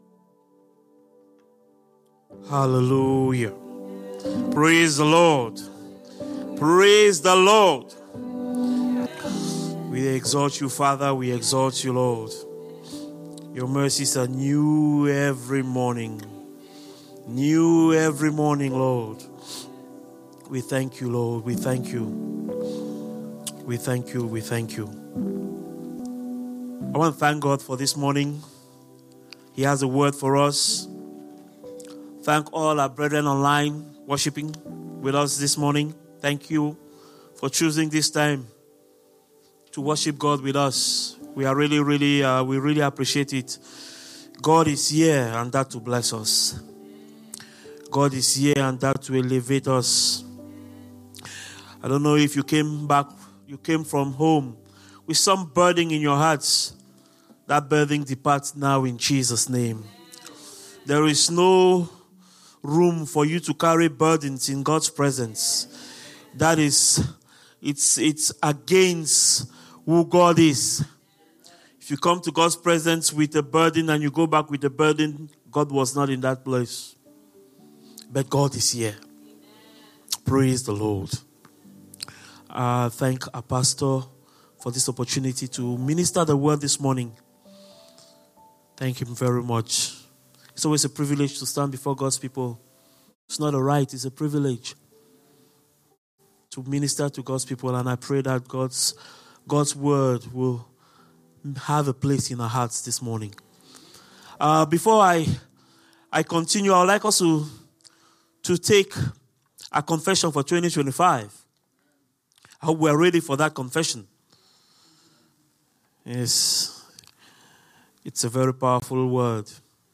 Sermons – Abundant Life International Church